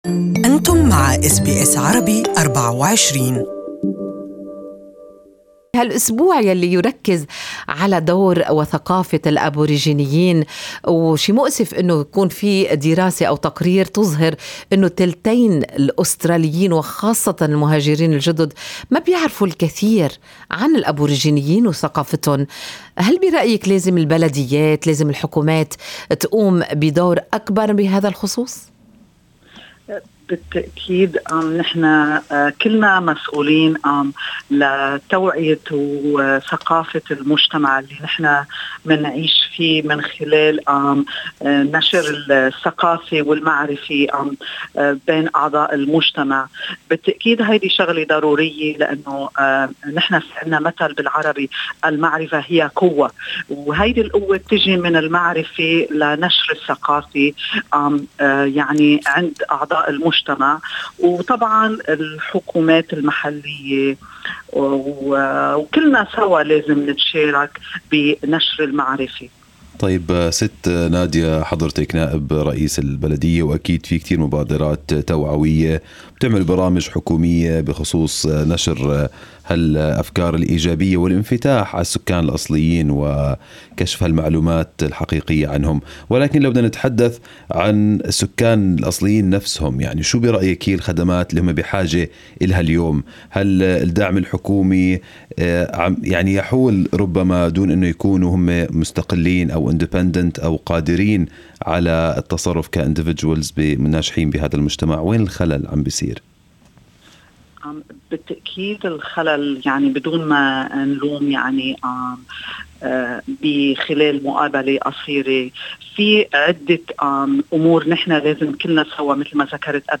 للإستماع إلى اللقاء يمكنكم الضغط على التدوين الصوتي أعلاه استمعوا هنا الى البث المباشر لاذاعتنا و لاذاعة BBC أيضا حمّل تطبيق أس بي أس الجديد على الأندرويد والآيفون للإستماع لبرامجكم المفضلة باللغة العربية.